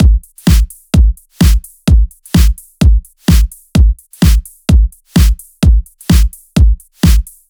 VFH3 128BPM Wobble House Kit